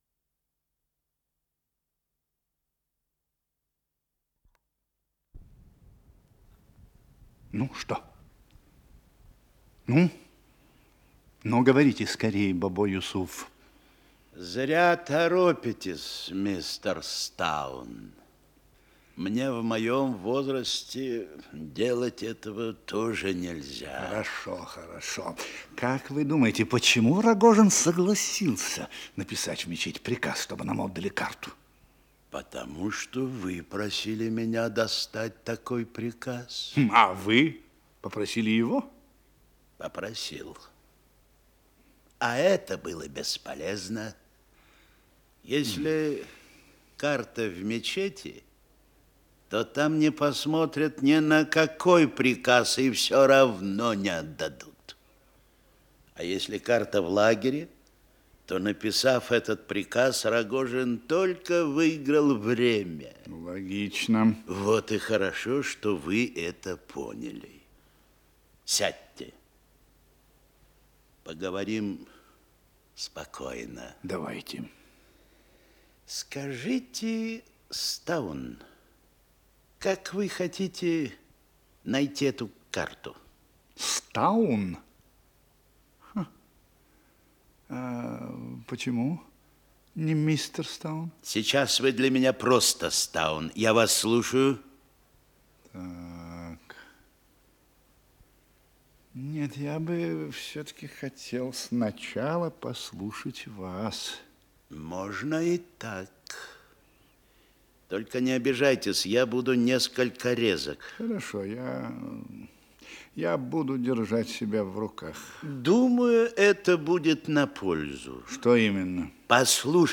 Исполнитель: Артисты московских театров Женская группа хора Симфонический оркестр
Радиопьеса